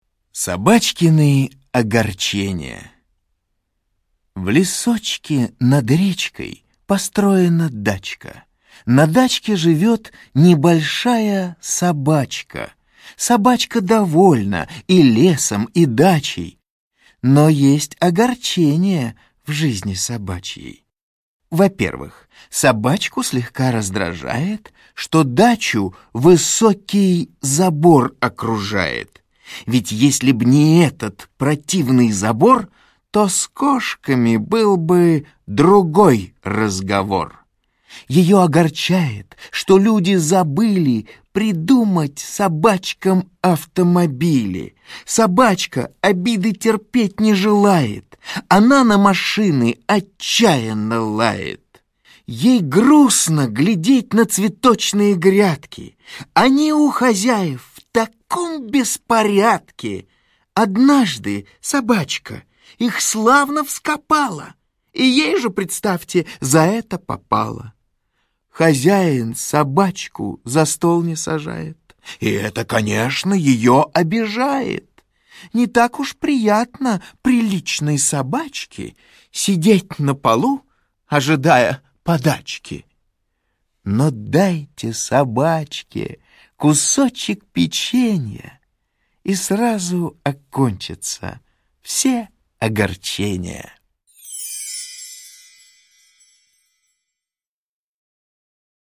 Собачкины огорчения - аудио стих Заходера - слушать онлайн